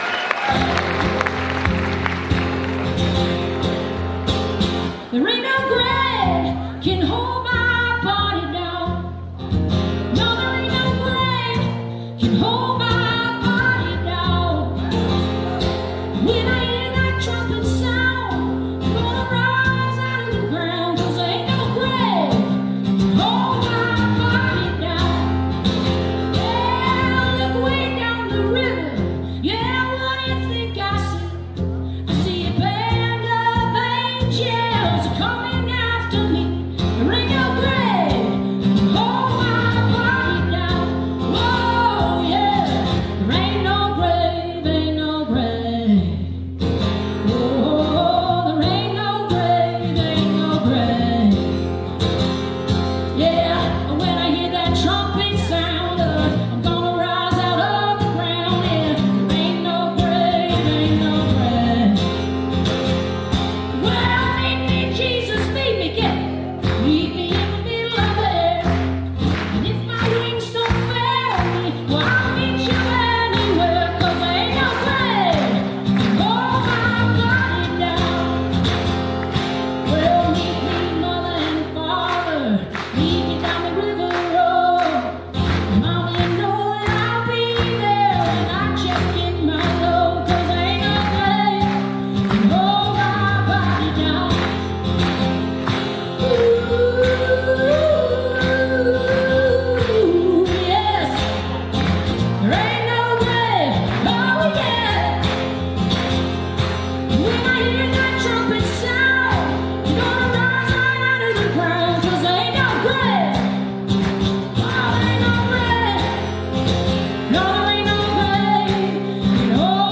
(band show)
(captured from a web stream)